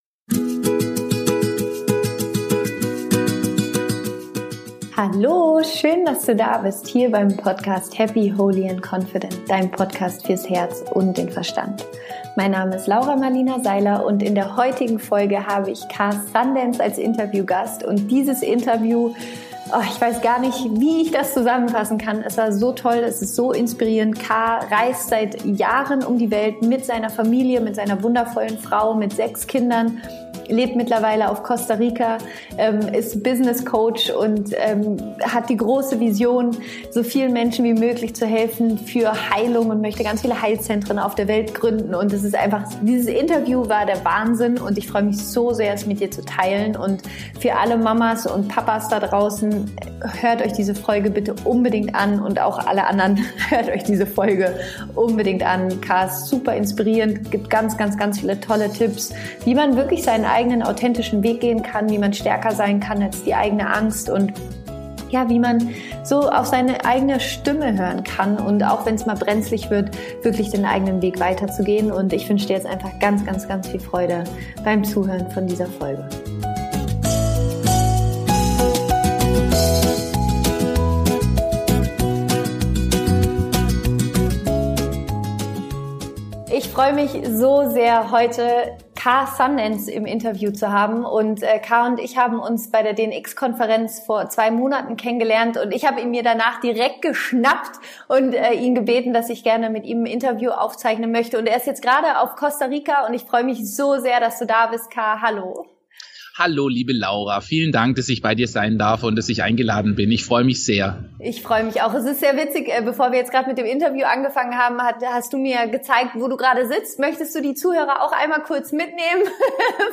Freunde der Sonne, dieses Interview ist pure Motivation und Inspiration!